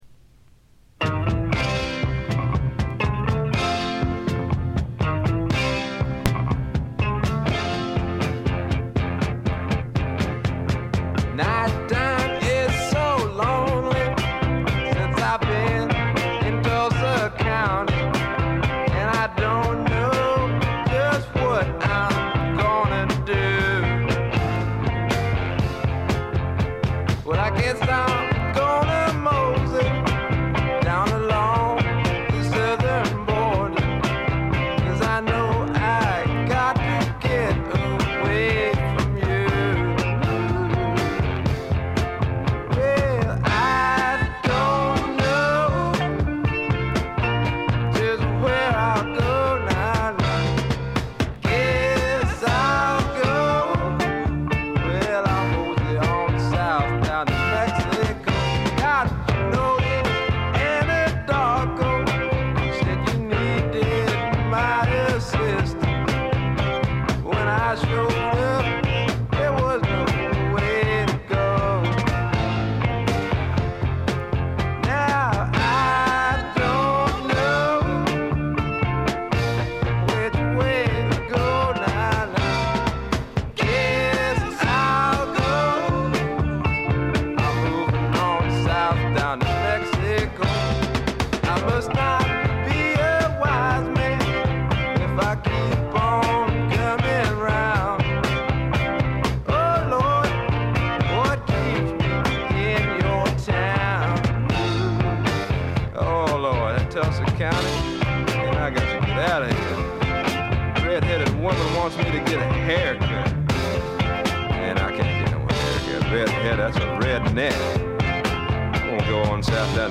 ごくわずかなノイズ感のみ。
まさしくスワンプロックの真骨頂。
試聴曲は現品からの取り込み音源です。
Vocal, Guitar, Keyboards